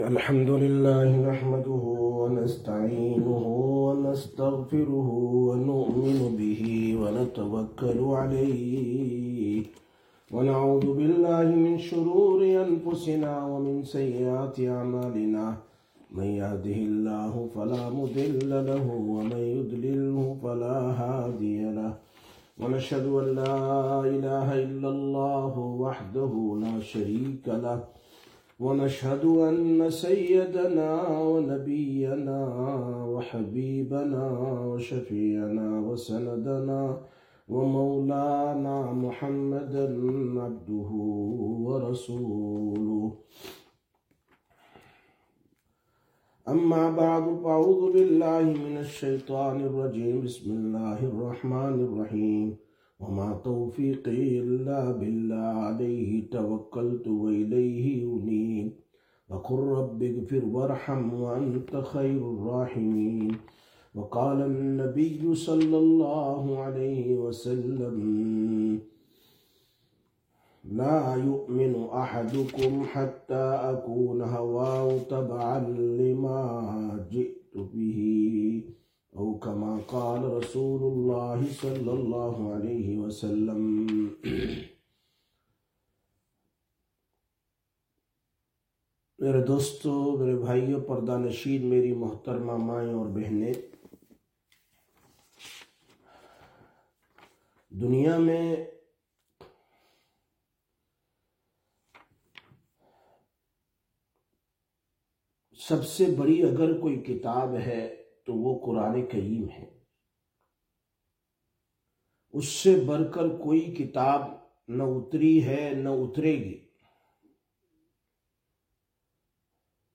18/06/2025 Sisters Bayan, Masjid Quba